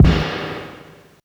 springstack_kick.wav